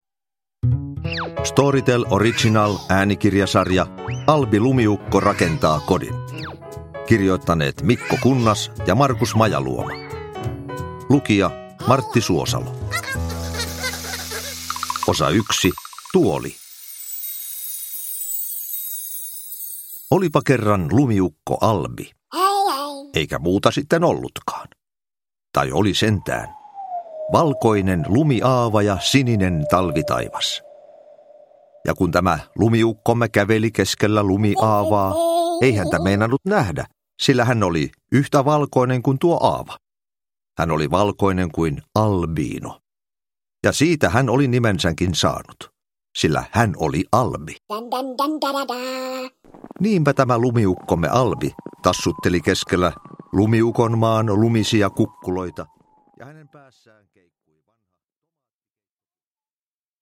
Albi rakentaa kodin: Tuoli – Ljudbok – Laddas ner
Uppläsare: Martti Suosalo